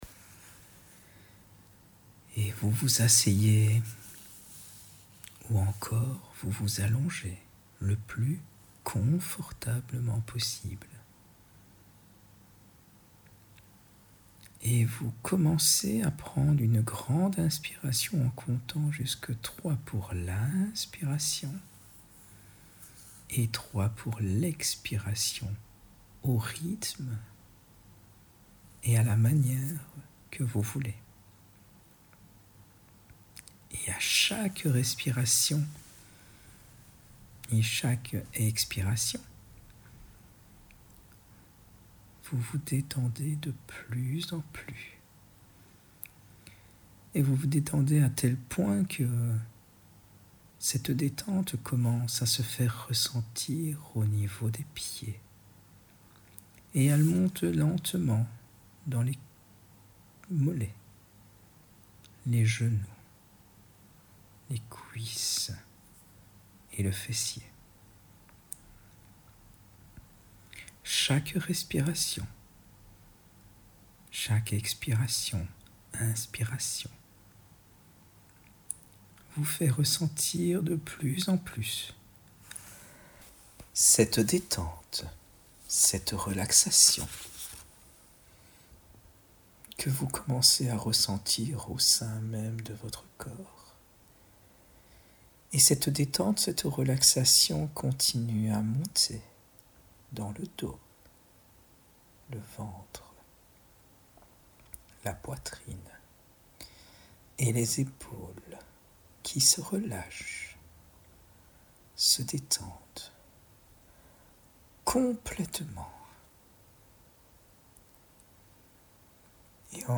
Relaxations guidées Le bateau sur le fleuve Your browser does not…